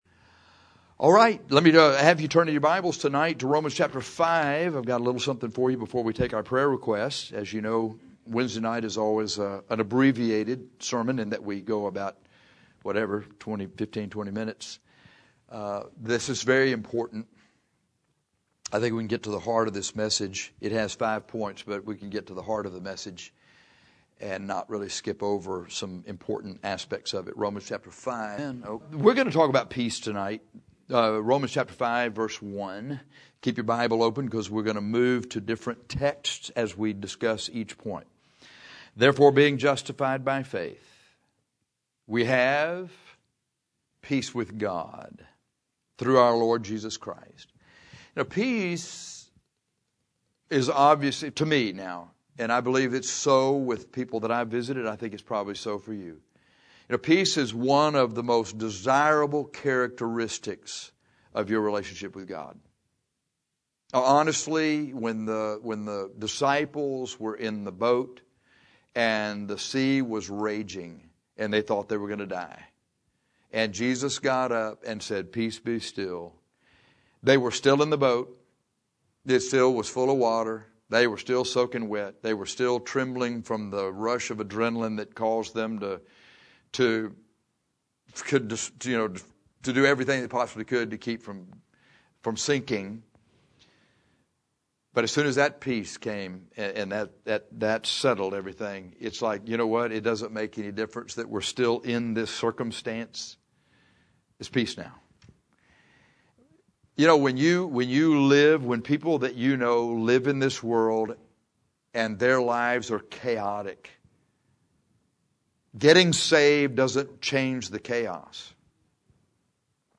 The Bible gives us clear instructions on how to have peace with God. This sermon will show you how to have that peace.